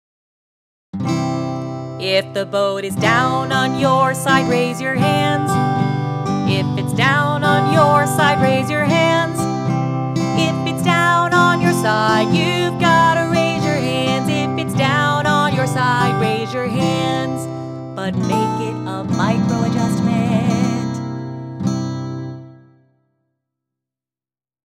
Boat-setting-jingle.m4a